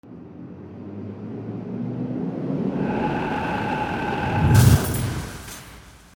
/ E｜乗り物 / E-10 ｜自動車
11 車が事故で壁にぶつかる 02